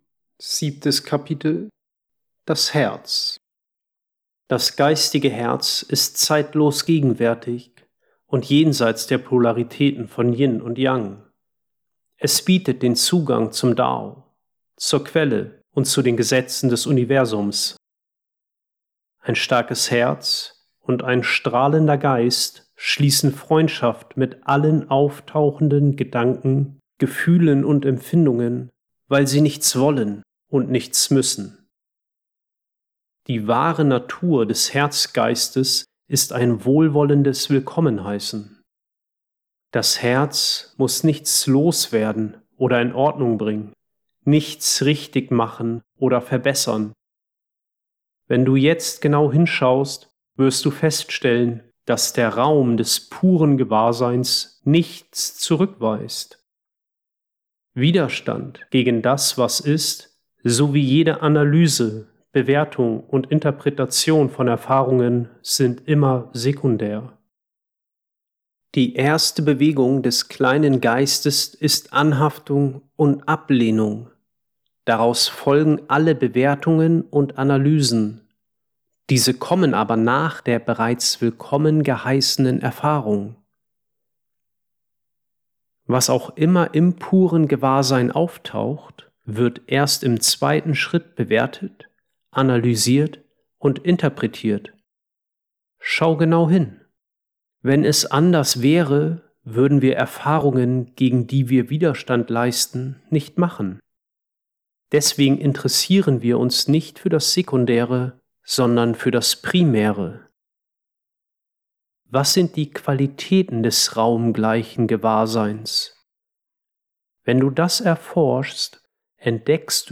• Ungekürzte Fassung